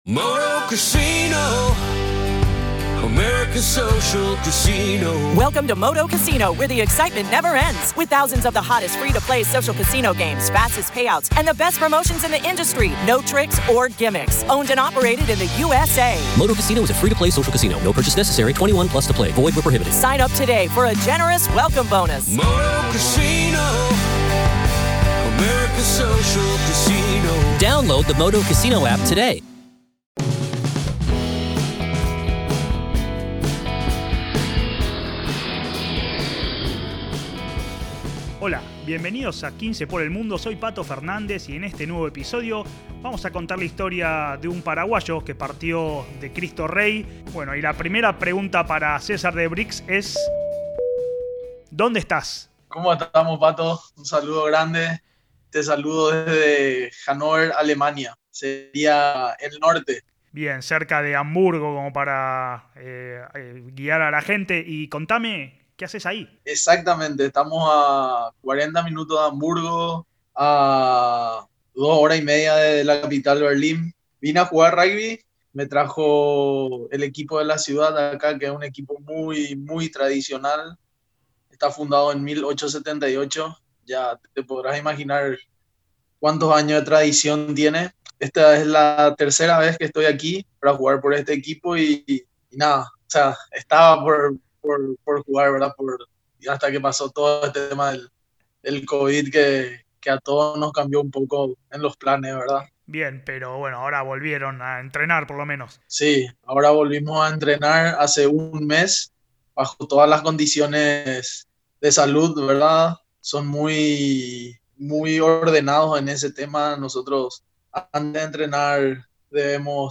¡Charlas de rugby con los protagonistas!